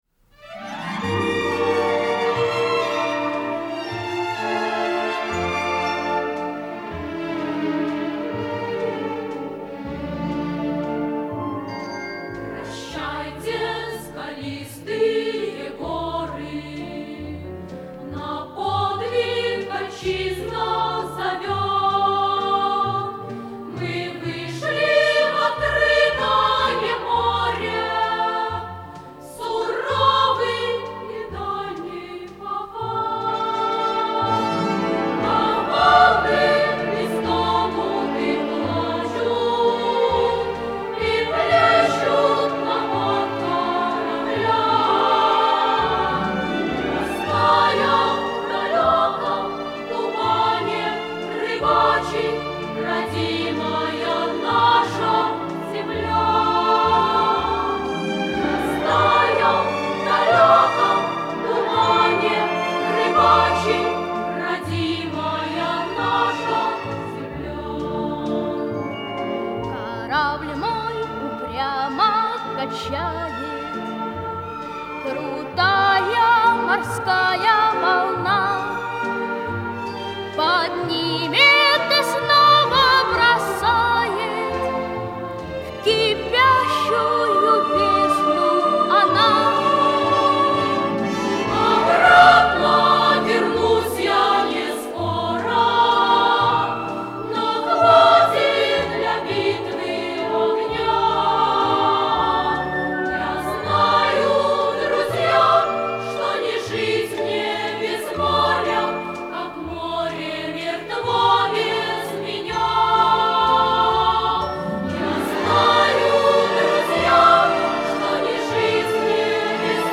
Известная морская песня в исполнении детского хора